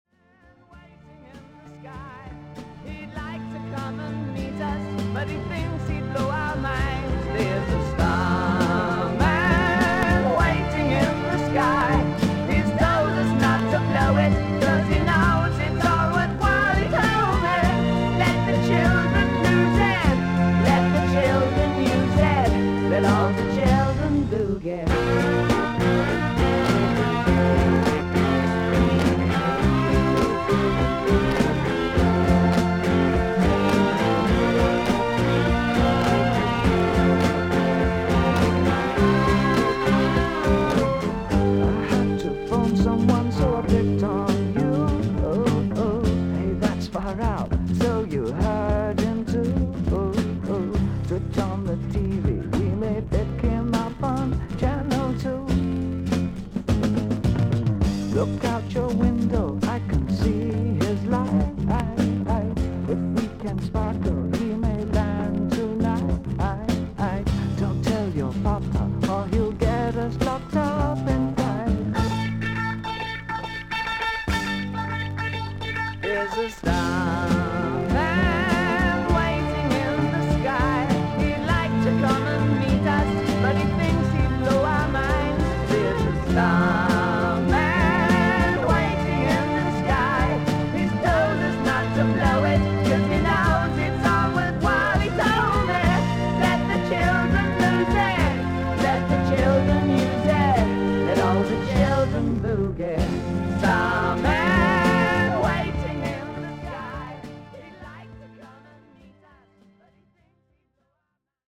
3回軽いノイズあり。
クリアな音です。